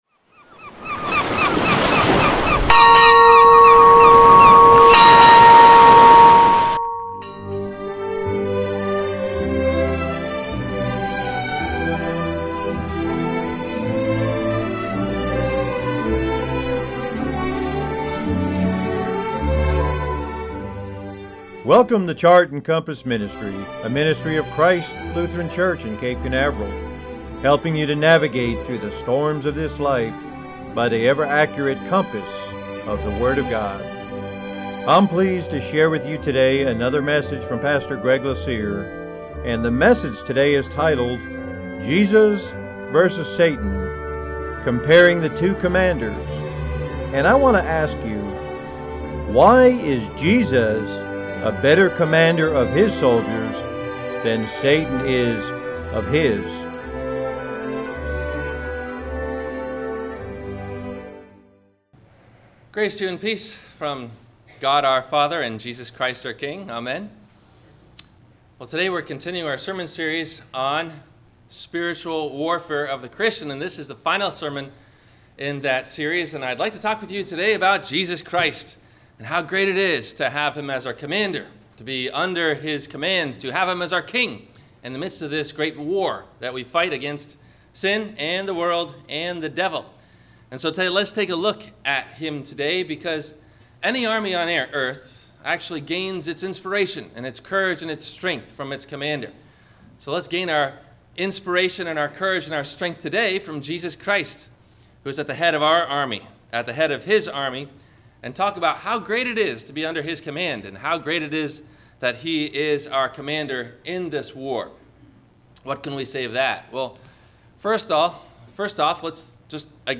Is It Wise To Follow Your Heart? – WMIE Radio Sermon – April 13 2015